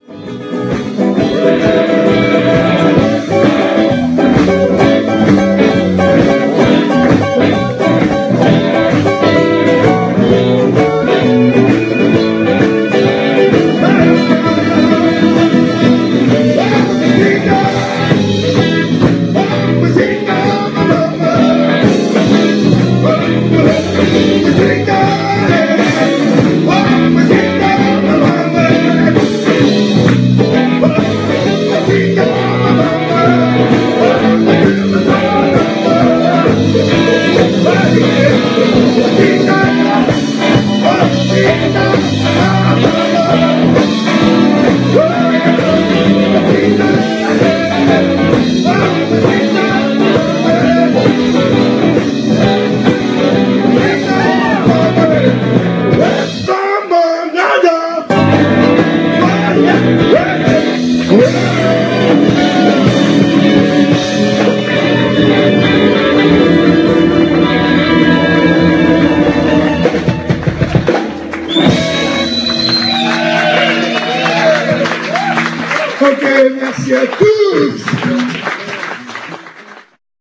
Dimanche 19/11 (après le Tremplin)